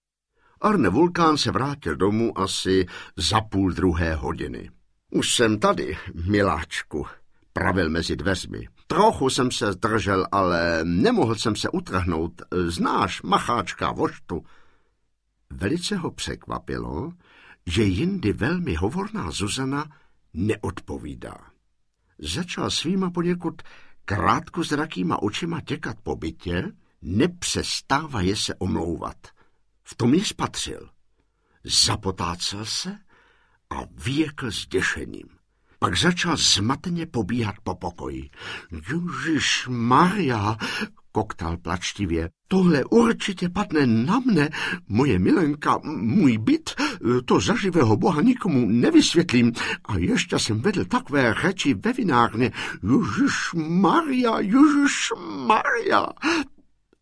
Ukázka z knihy
• InterpretPetr Nárožný
draculuv-svagr-audiokniha